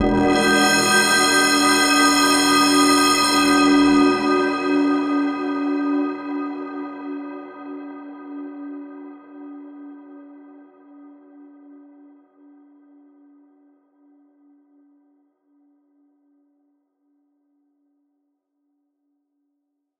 metallic_whistle.wav